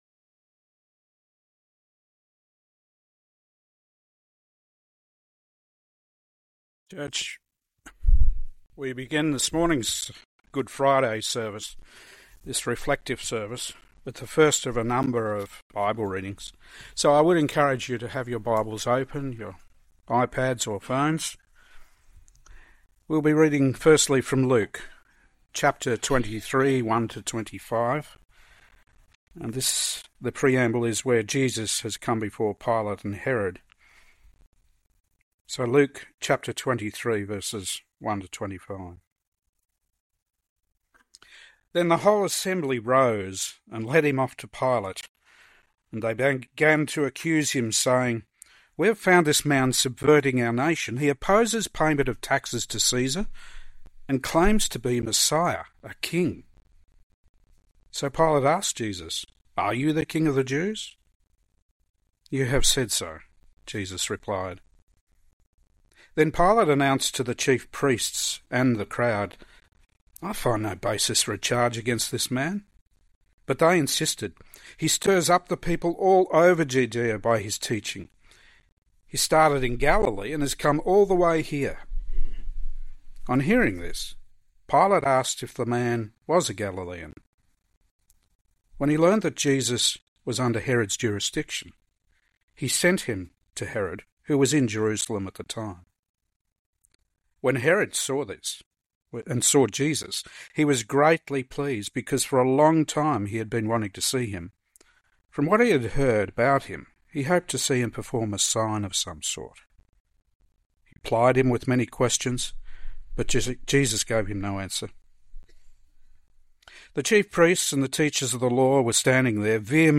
Good Friday 2026
One-Off Sermons